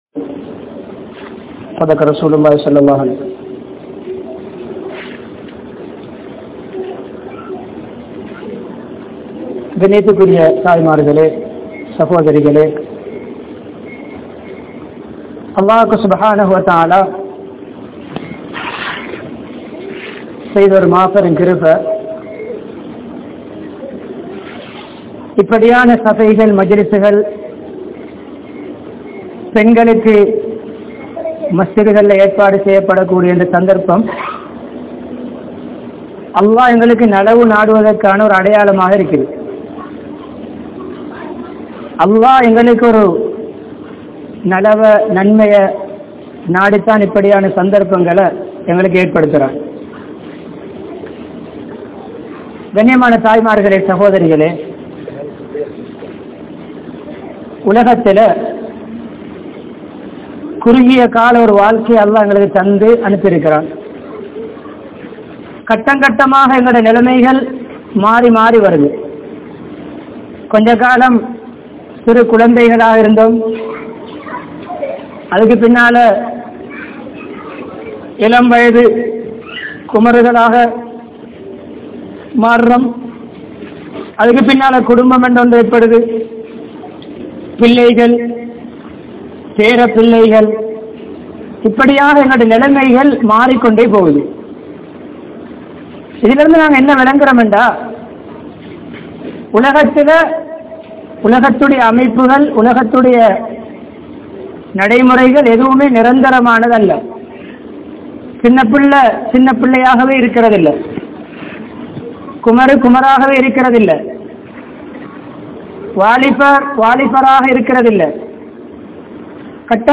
Islaamiya Pengal Entraal Yaar? (இஸ்லாமிய பெண்கள் என்றால் யார்?) | Audio Bayans | All Ceylon Muslim Youth Community | Addalaichenai
Crow Island Masjidh